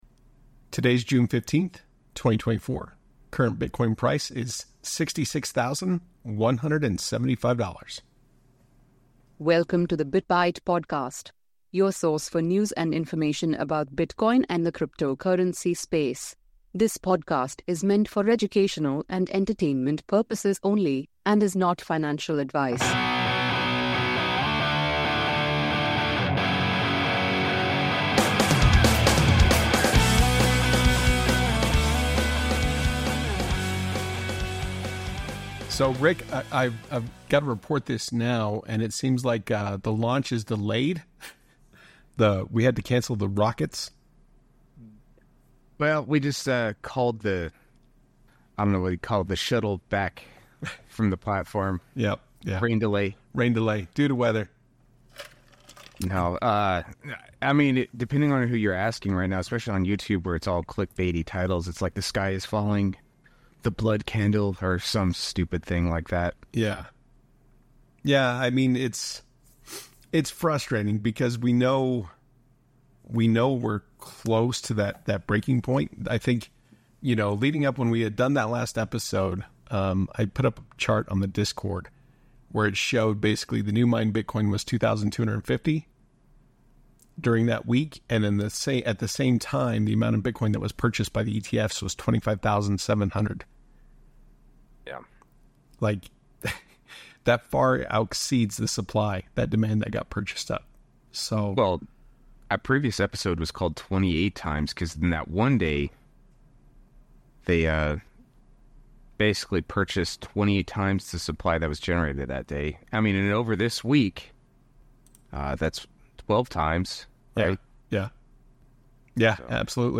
Ep121 The guys talk about the recent price action over the past month. They also talk about the CPI report for May.